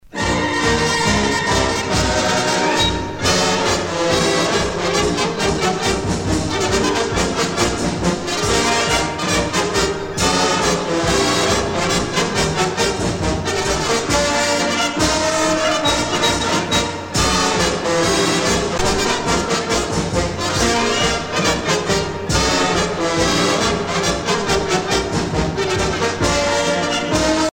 Fonction d'après l'analyste gestuel : à marcher
Usage d'après l'analyste circonstance : militaire
Pièce musicale éditée